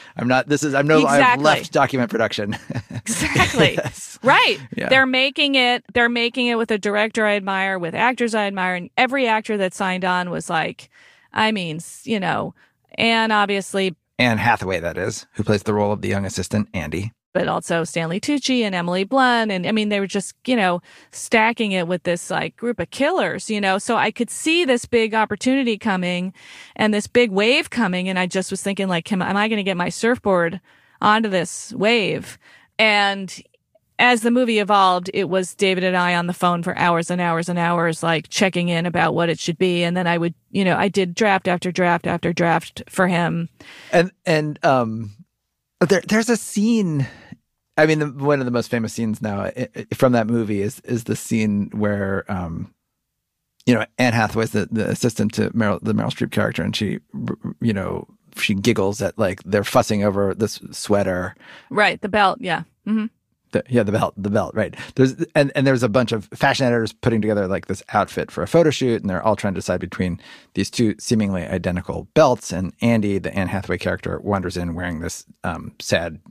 Play Rate Listened List Bookmark Get this podcast via API From The Podcast 1 8 Candid conversations with entrepreneurs, artists, athletes, visionaries of all kinds—about their successes, and their failures, and what they learned from both. Hosted by Alex Blumberg, from Gimlet Media.